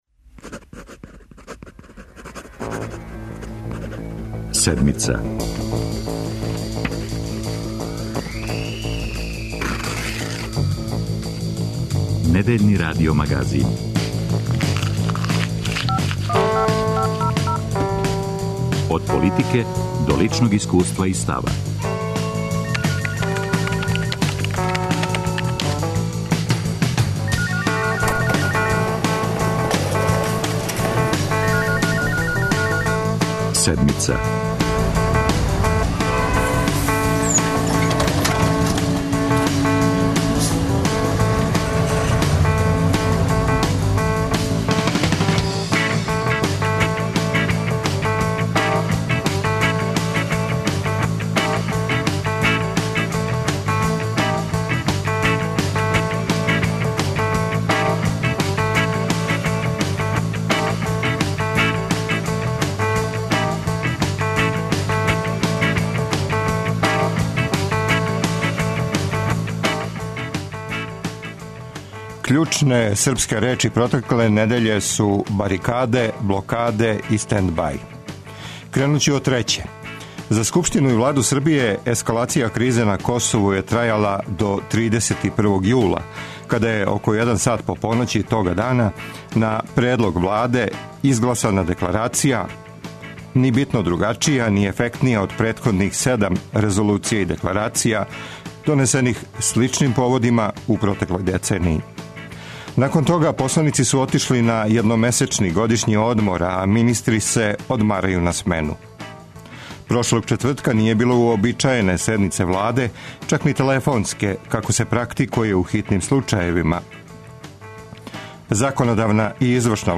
Пратимо најновије догађаје на Косову и Метохији. Током емисије укључићемо наше репортере који се налазе на лицу места, а чућете и изјаве државних званичника.